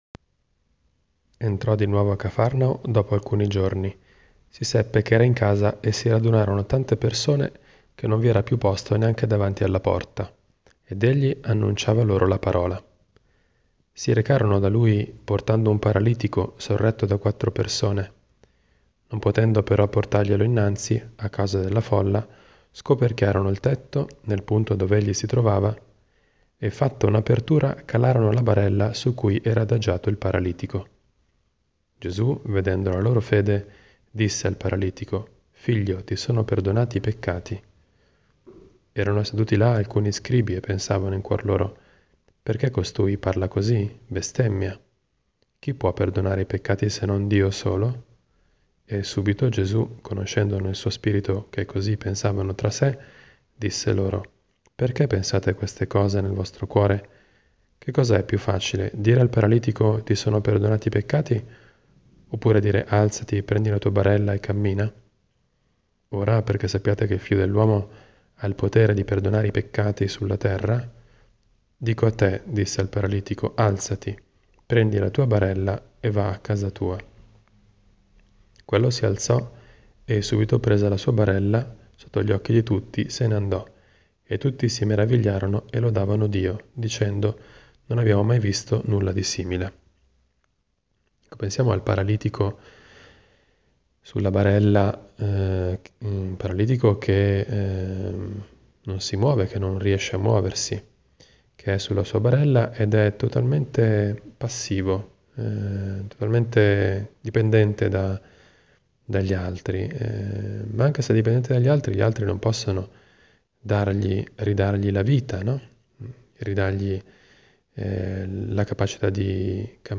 Commento al vangelo (Mc 2, 1-12) del 12 gennaio 2018, venerdì della I settimana del Tempo Ordinario.